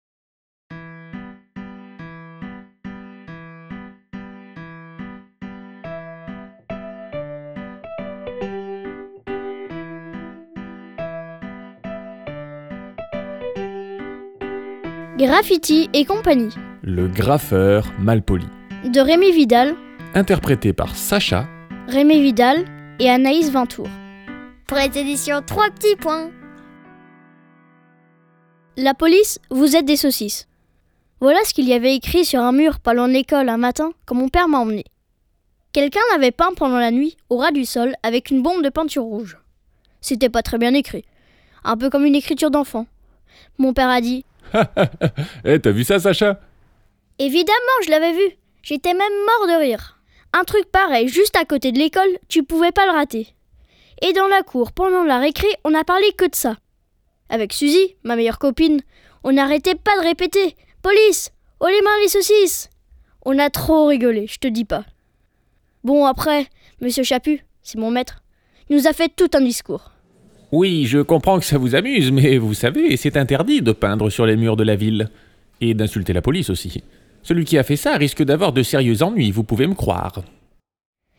Fiction sonore